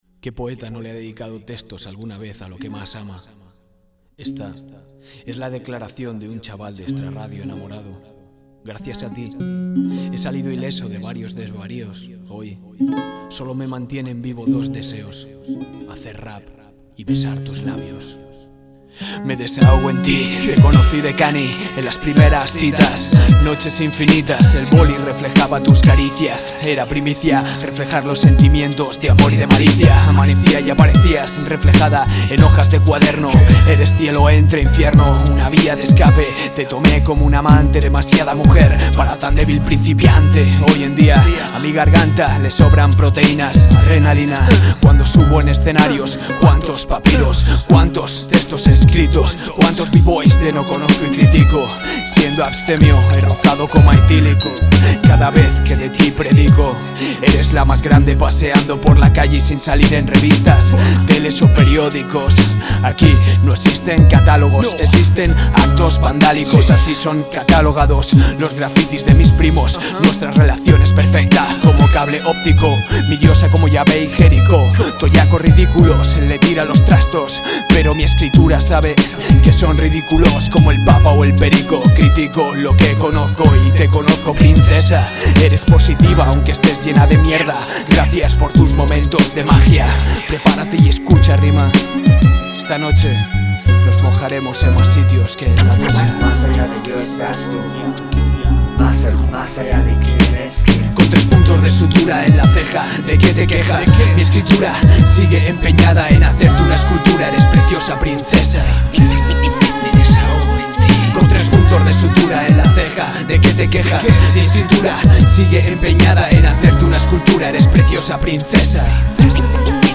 Grabado y mezclado en los estudio